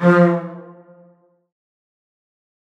Fx (String).wav